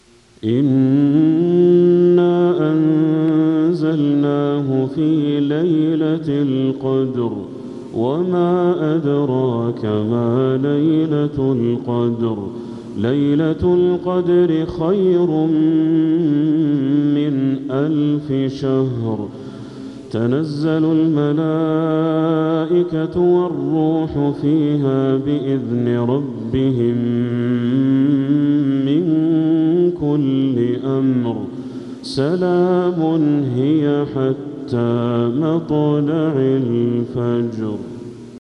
سورة القدر كاملة | صفر 1447هـ > السور المكتملة للشيخ بدر التركي من الحرم المكي 🕋 > السور المكتملة 🕋 > المزيد - تلاوات الحرمين